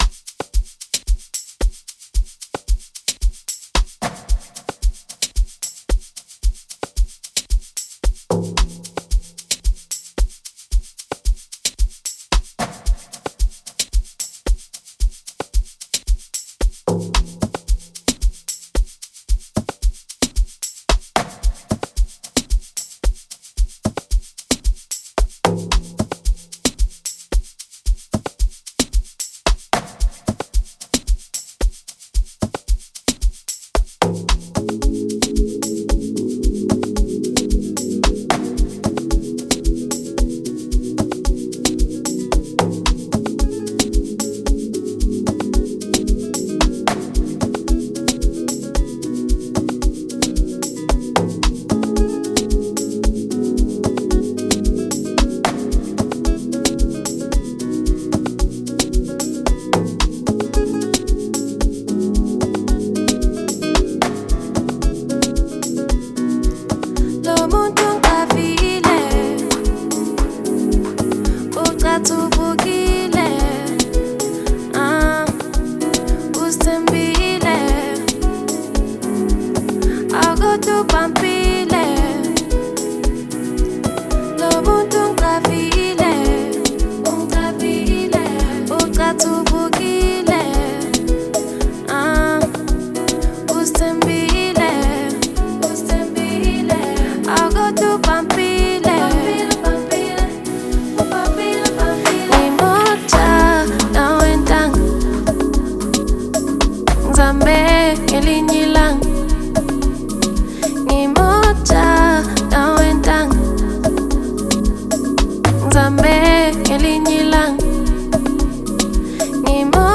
soulful piano record
The song is very soft and easy listening.
Over a blissful piano production